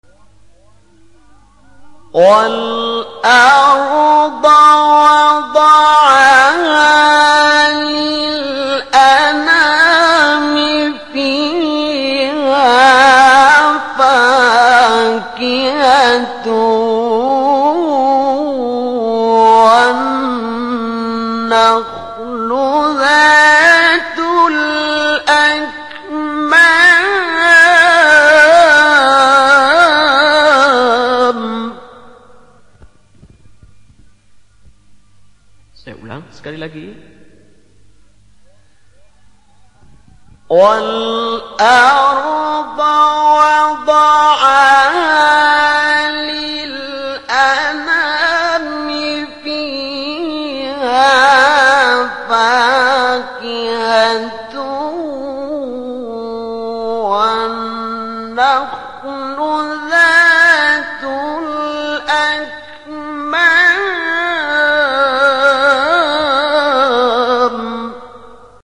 بیات-اصلی-جواب2.mp3